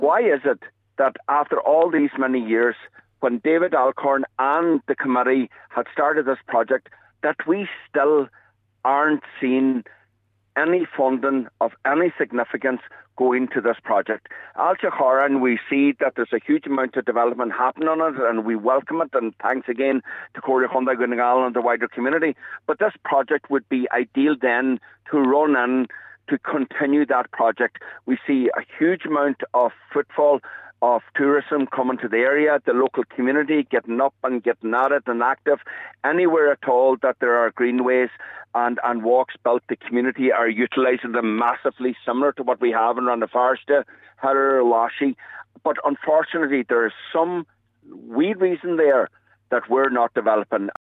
Councillor Mac Giolla Easbuig says he wants clarity on the reasons behind the slow progress: